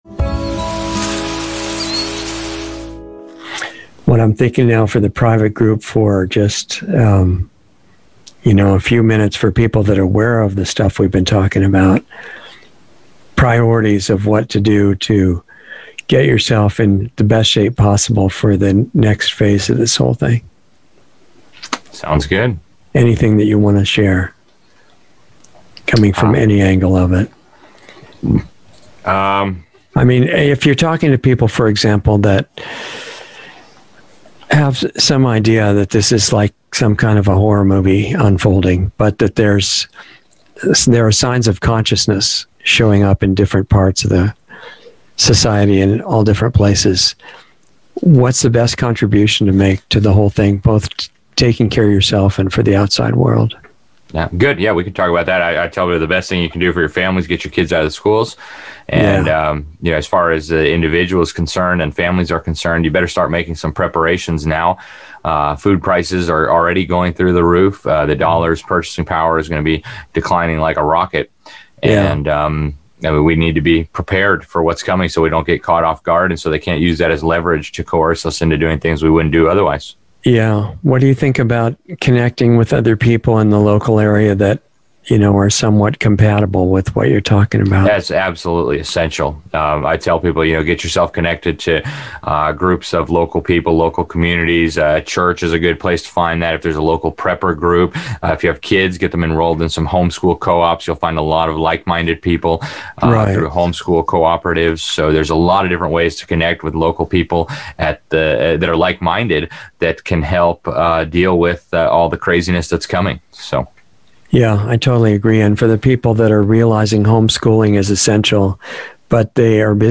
Insider Interview 5/11/21